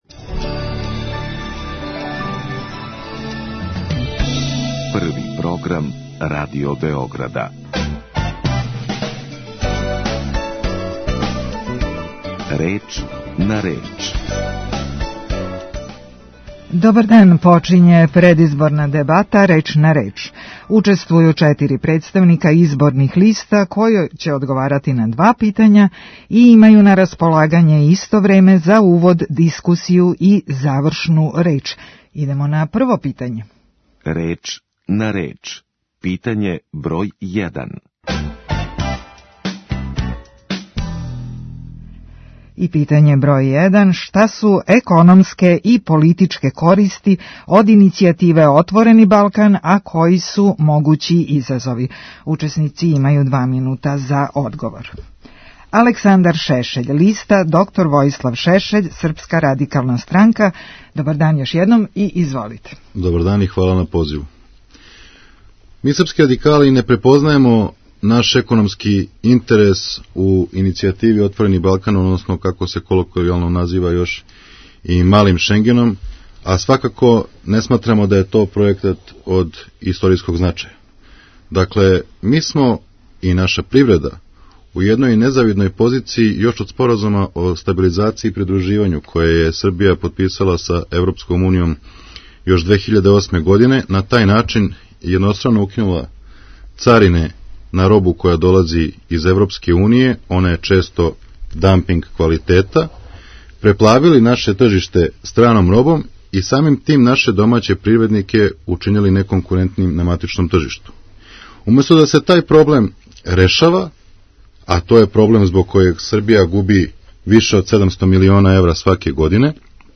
Разговор у емисији одвија се према унапред одређеним правилима тако да учесници имају на располагању исто време за увод, дискусију и завршну реч.